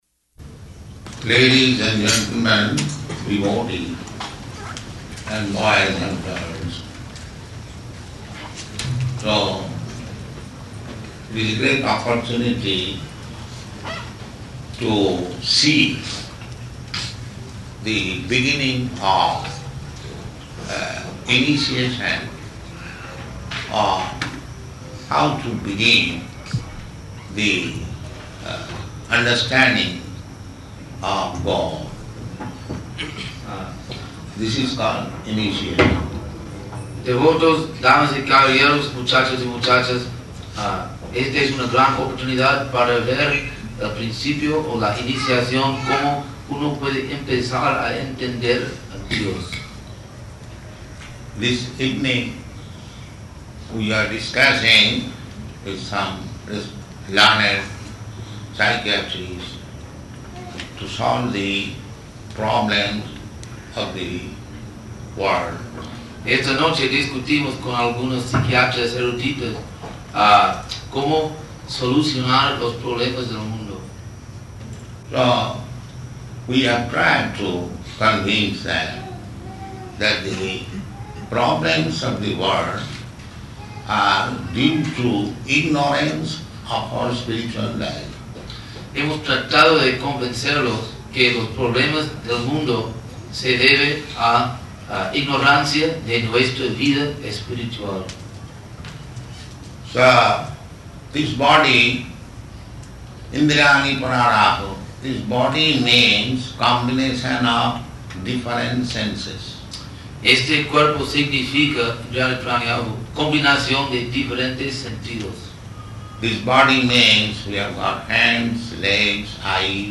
Initiation Lecture
Type: Initiation
Location: Caracas